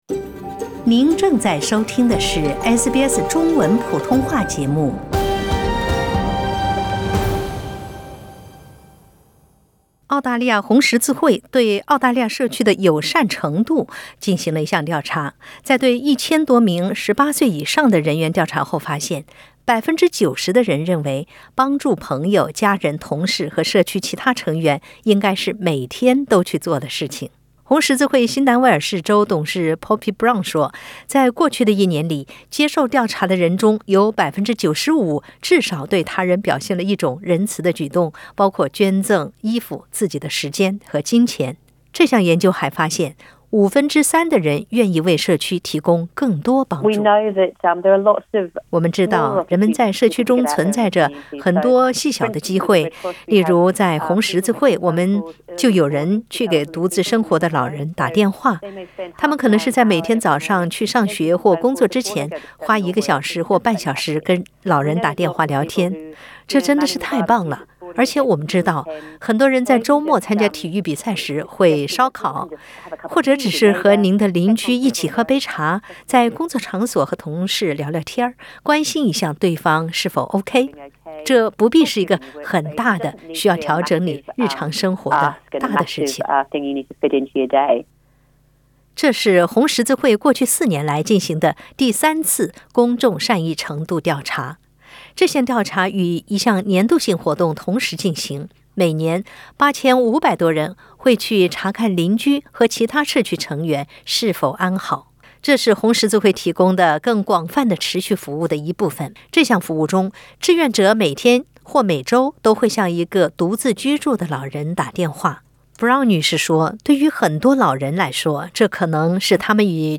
从正在肆虐的新冠病毒到持续数月的山林火灾，澳洲人的友善程度是否已被改变？点击上方图片收听录音报道。